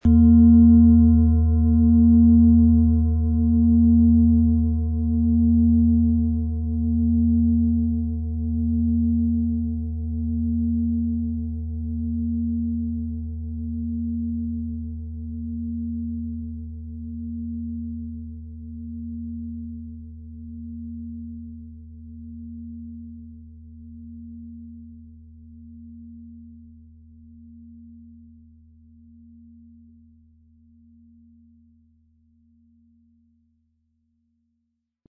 Planetenton 1
Es ist eine Planetenklangschale Alphawelle aus einem kleinen Meisterbetrieb in Asien.
MaterialBronze